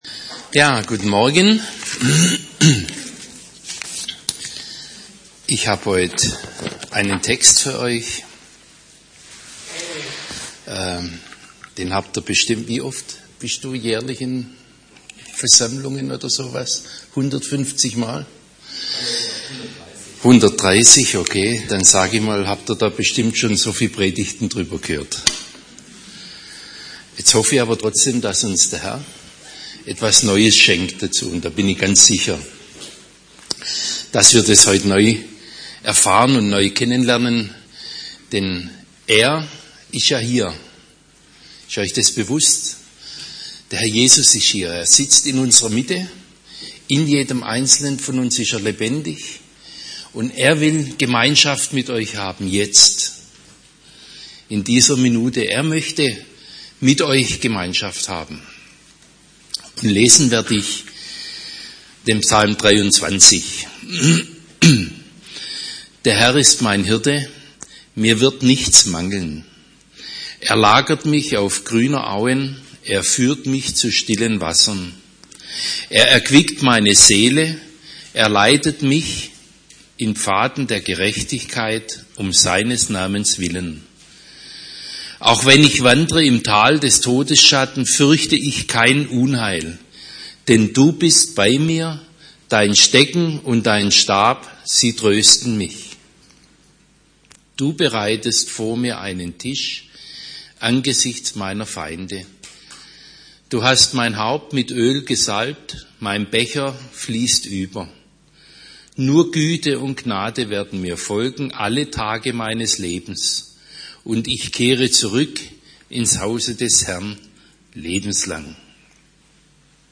Predigten – Seite 59 – HOPE Kirche Podcast